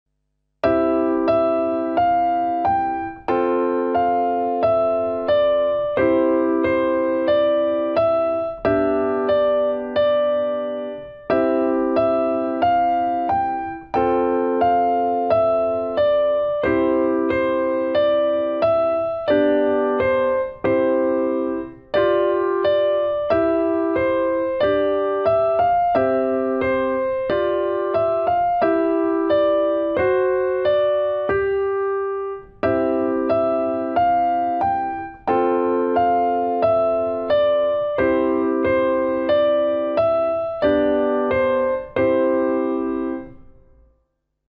Óda na radost (Ode to joy) na klavír - videolekce a noty pro začátečníky
4-Oda-na-radost-s-akordy-90.mp3